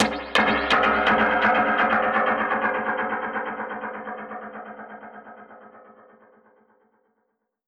Index of /musicradar/dub-percussion-samples/125bpm
DPFX_PercHit_E_125-02.wav